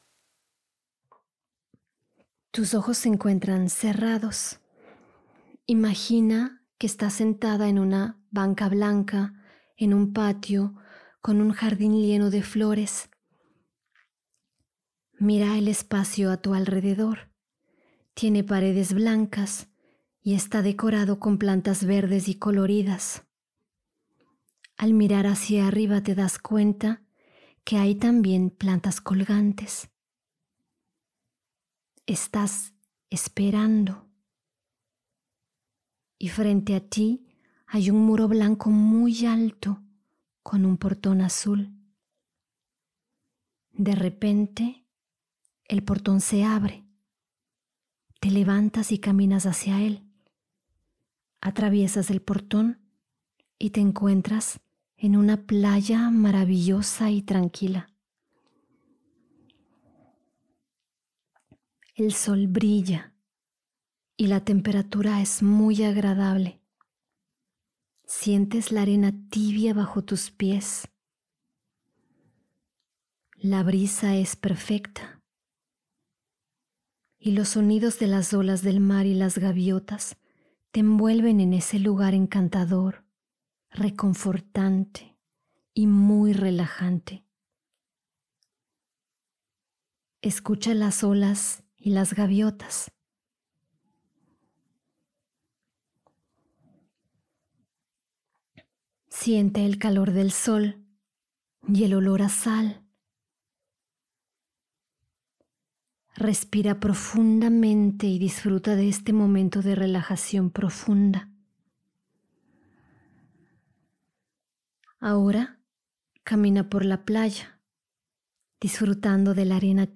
Autosugestión: Ejercicio de visualización 1